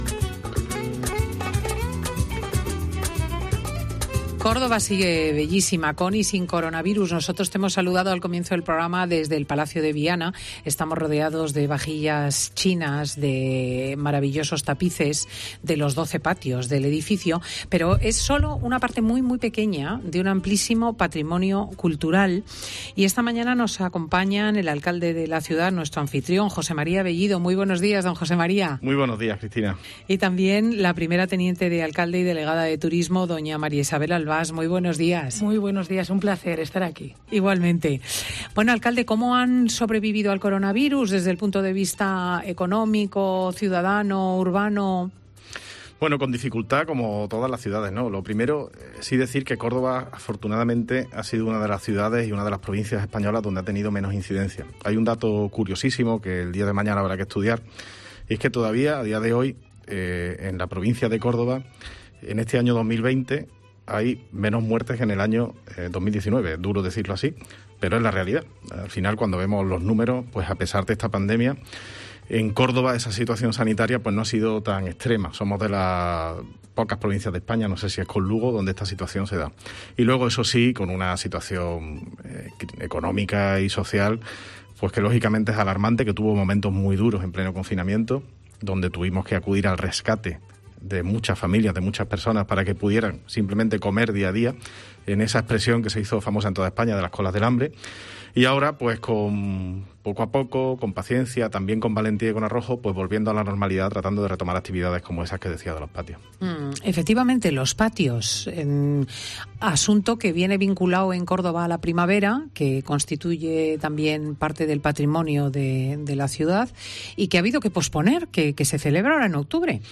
El alcalde de Córdoba, José María Bellido, ha pasado por los micrófonos de Fin de Semana COPE